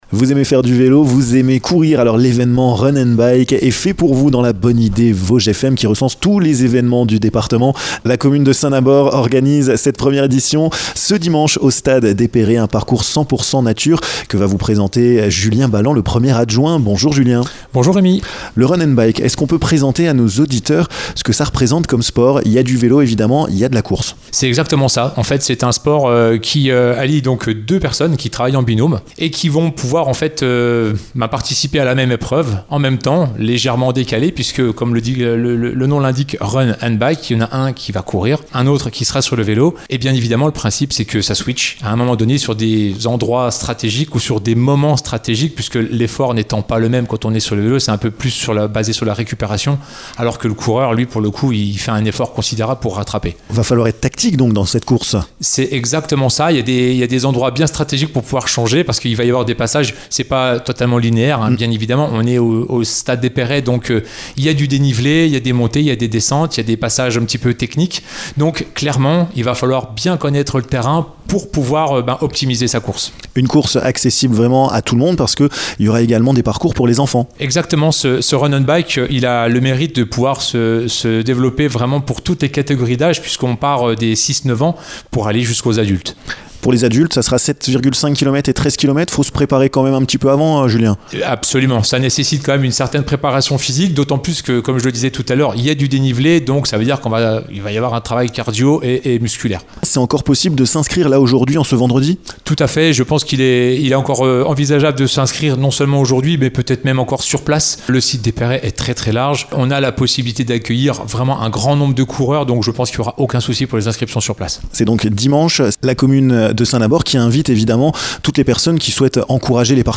Julien Balland, 1er adjoint à la mairie de Saint-Nabord, vous explique le déroulement de cette journée.